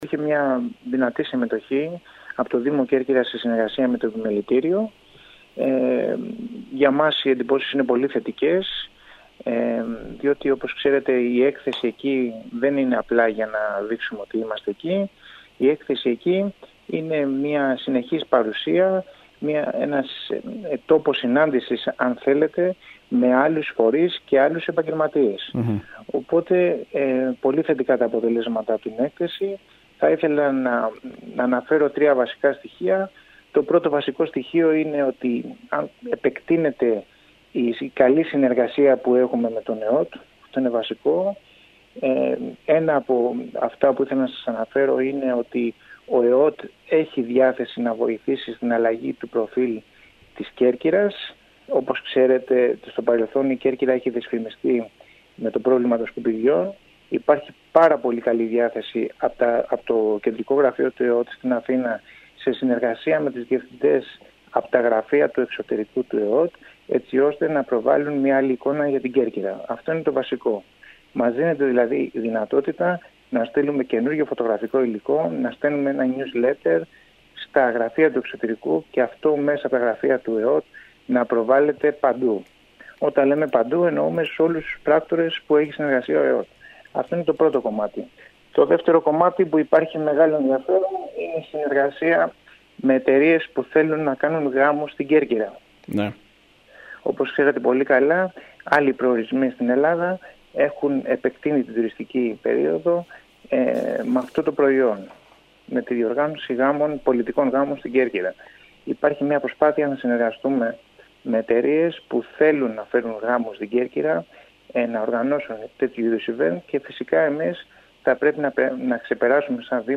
Κέρκυρα: Ο Αντιδήμαρχος Τουρισμού Π. Βαρούχας για την WTM – Philoxenia
Ικανοποιημένος από την παρουσία της Κέρκυρας στη wtm του Λονδίνου, δήλωσε ο αντιδήμαρχος τουρισμού του Δήμου Κεντρικής Κέρκυρας, Παναγιώτης Βαρούχας. Ο κ. Βαρούχας τόνισε ότι τόσο η πολιτική εκπροσώπηση του νησιού όσο και οι επαγγελματίες του χώρου, είχαν την ευκαιρία να πραγματοποιήσουν εκτεταμένες συναντήσεις και συζητήσεις με εκπροσώπους του βρετανικού τουρισμού και στόχο την αποτύπωση της τουριστικής πραγματικότητας μετά την κατάρρευση του τουριστικού κολοσσού της Thomas Cook. Στόχος υπήρξε επίσης η αντιστροφή της εικόνας που είχε μέρος των βρετανών τουριστικών πρακτόρων για την Κέρκυρα εξαιτίας του προβλήματος διαχείρισης των απορριμμάτων.